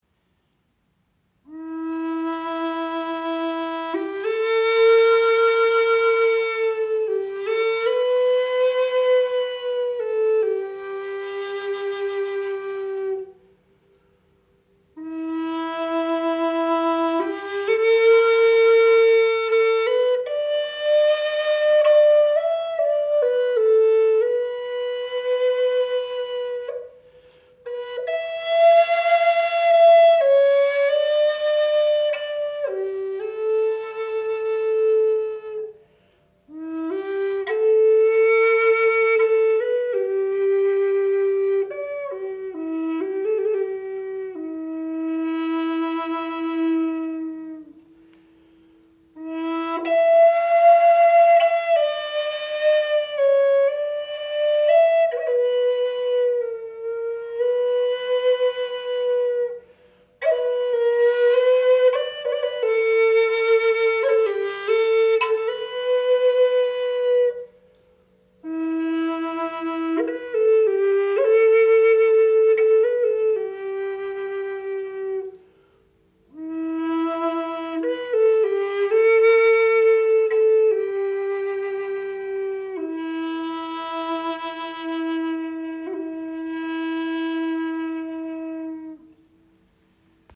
• Icon Gesamtlänge 63,5  cm, 25  mm Innenbohrung – warmer, klarer Klang
Präzise gestimmt entfaltet die Flöte klare, harmonische und tragende Töne, die Herz und Seele berühren.
Kraftvolle Wolf-Chief Gebetsflöte aus Myrtle und Ziricote in E-Moll. Präzise gestimmt, warm im Klang und voller Seele – ein seltenes, signiertes Sammlerstück.
In E-Moll gestimmt, entfaltet die Flöte einen vollen, tiefen und zugleich klaren Klang, der getragen und präsent im Raum steht.